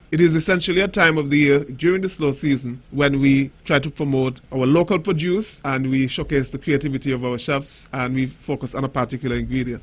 That was the Permanent Secretary in the Ministry of Tourism-Nevis, Mr. John Hanley.